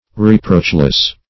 Reproachless \Re*proach"less\, a. Being without reproach.